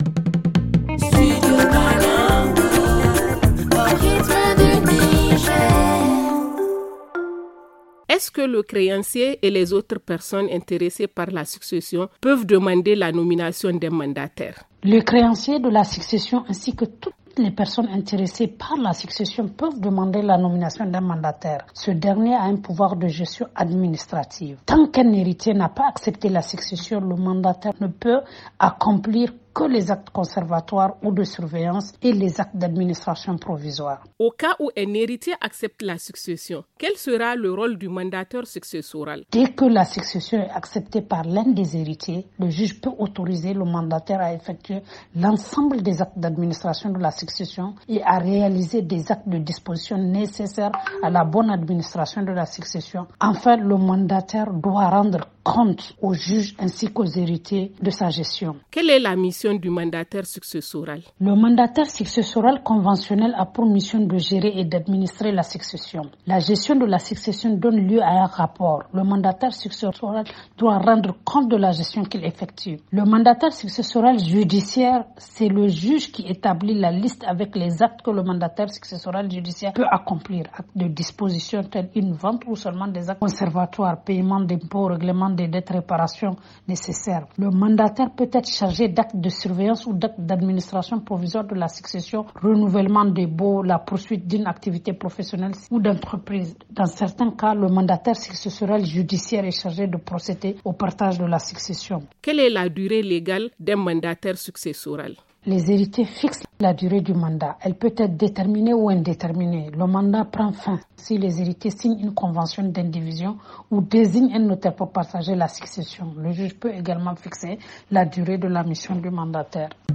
FR Le magazine en français https